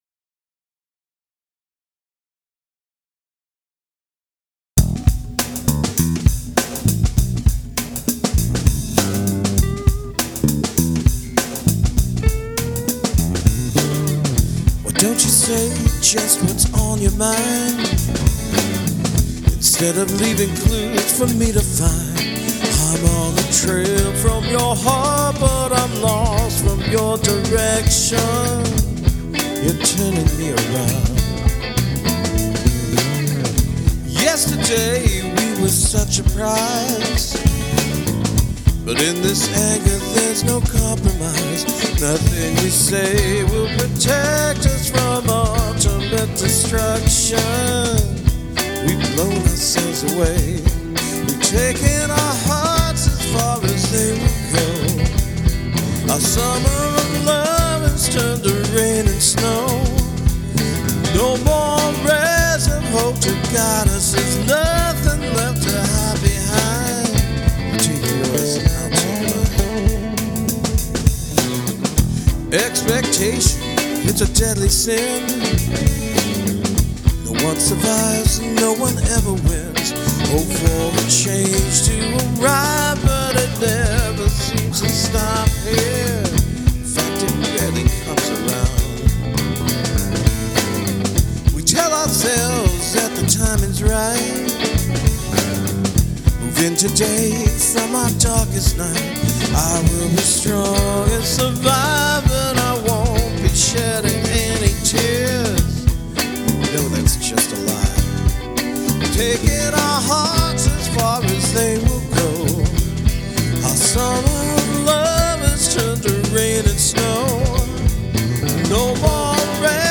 So the other day, I came up with a funky groove. It started out as a simple blues in Em, bouncing back and forth between Em7 and Bm7. I knew I didn’t want to just stick to a 1-4-5 kind of pattern, so I added some altered chords to kind of “jazz” it up a bit.
Rhythm (left and right sides): Squier Classic Vibe Tele (50’s)
Lead: Gibson Limited Run Nighthawk 2009
• I just love that vintage Marshall tone.